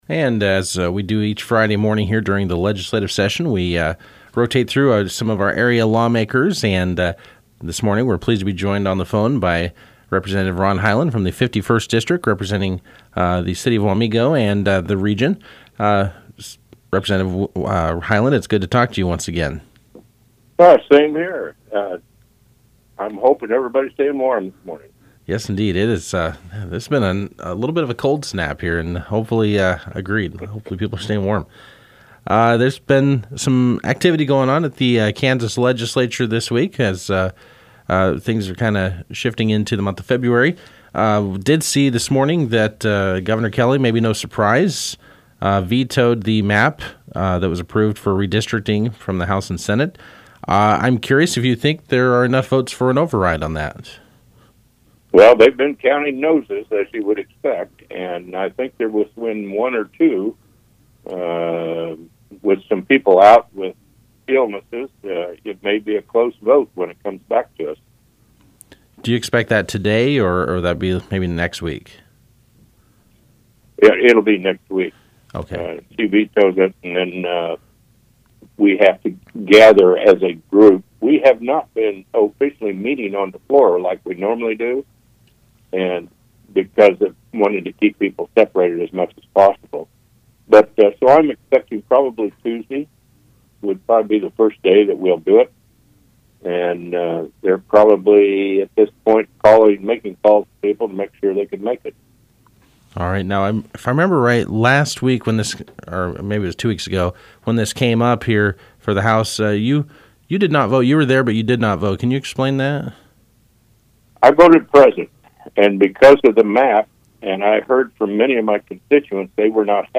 On Friday morning we spoke with 51st District State Rep. Ron Highland with our Weekly Legislative Update.